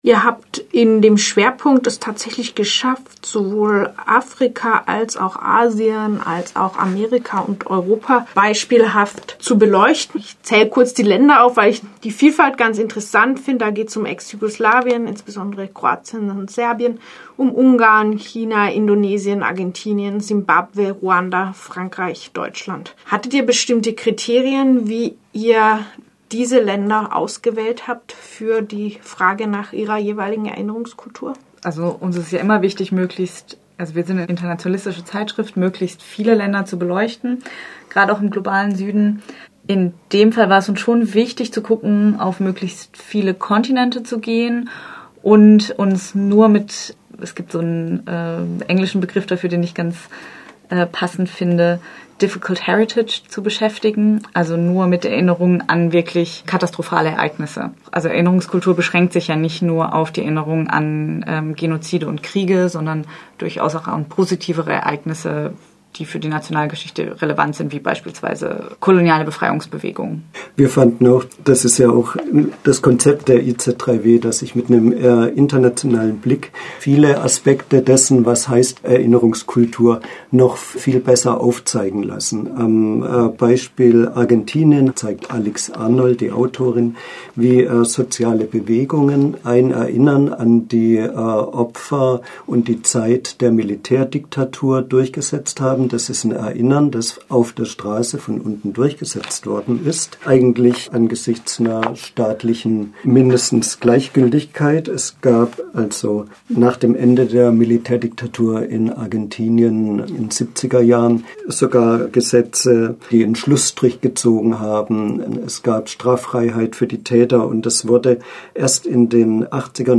Interview kurz: